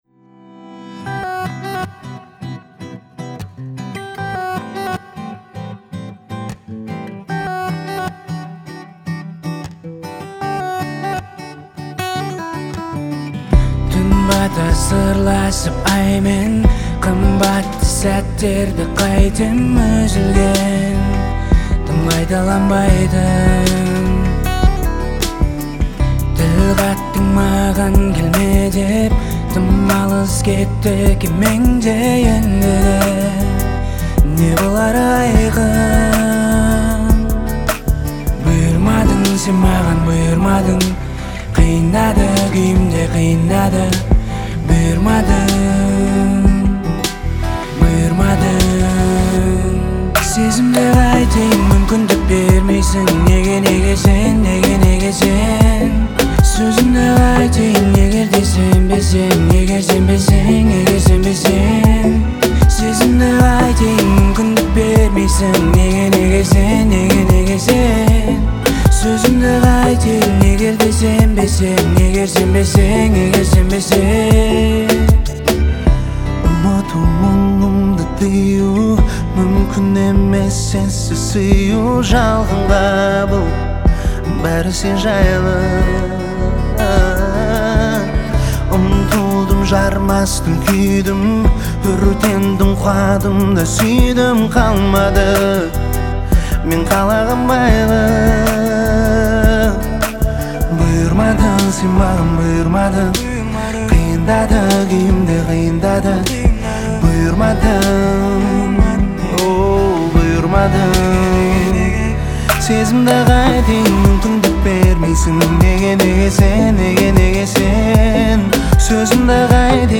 это яркая композиция в жанре казахского поп